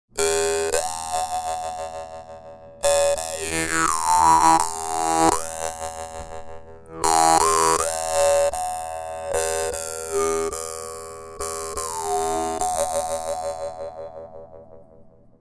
VIETNAMESE JAW HARP · DAN MOI
Hand-made by a small number of excellent local craftsmen, this brass instrument captivates even novices by being easily playable and having a brilliant sound, rich in overtones.
This ensures a wide variety of sounds, especially in the high ranges."